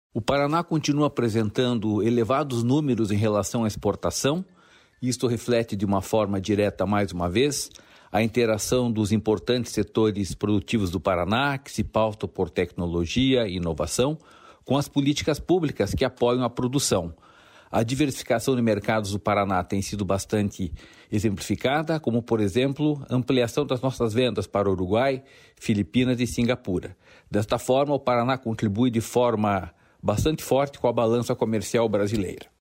Sonora do diretor-presidente do Ipardes, Jorge Callado, sobre as exportações do Paraná em 2025